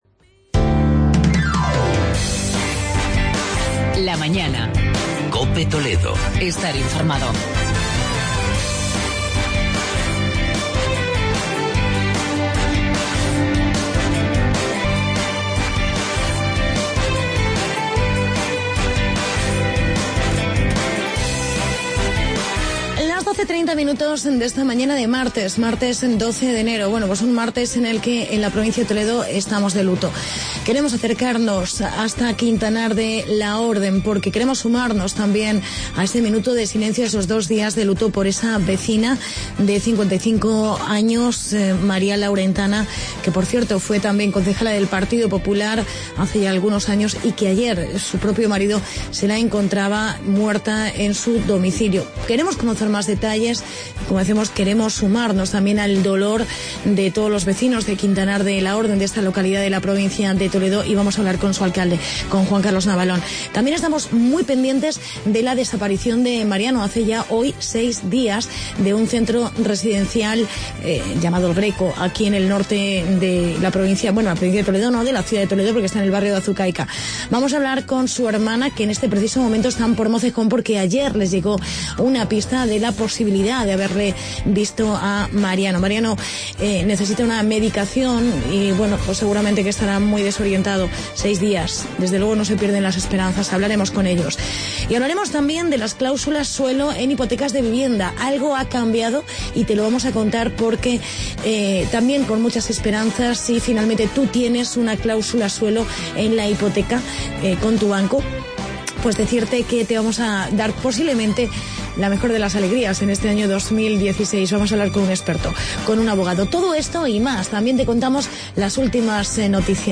Hablamos con el alcalde de Quintanar sobre el presunto asesinato de una vecina.